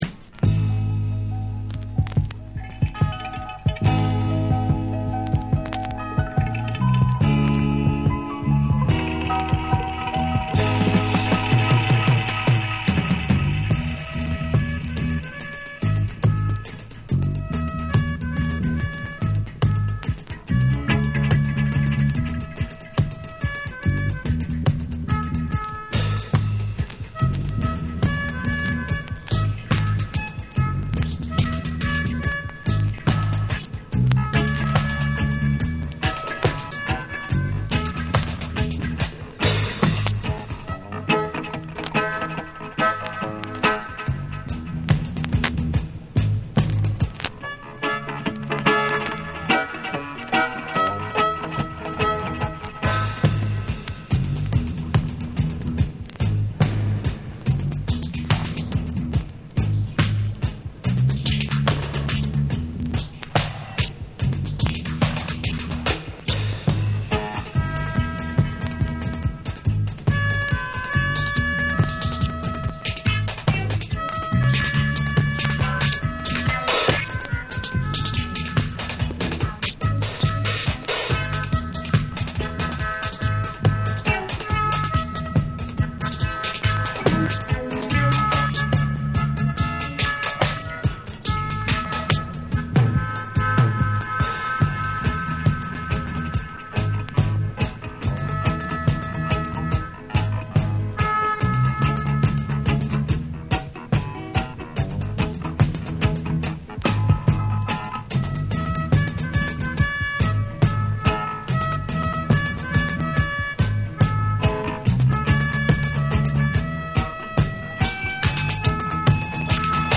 彼ならではの浮遊感あるメロディカが、幻想的に響き渡るスピリチュアルなダブの世界。